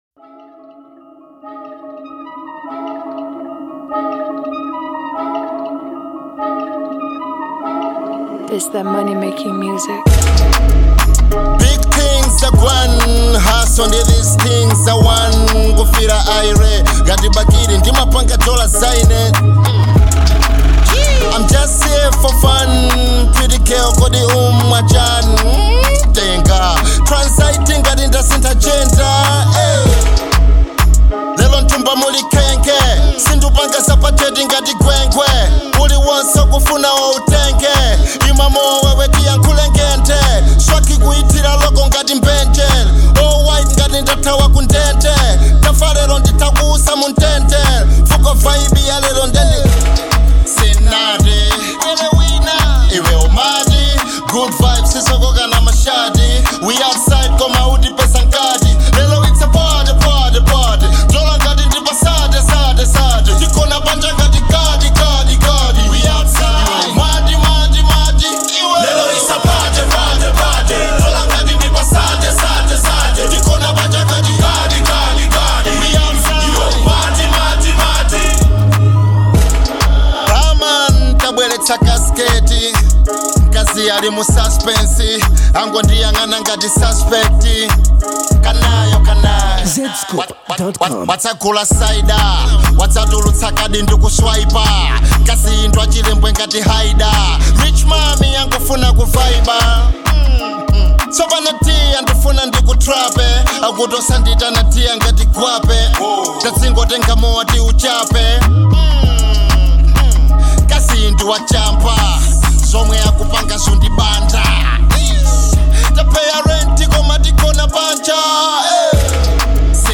Demonstrating his prowess in the Dance Hall genre
pulsating beat
seamless blend of rhythm and melody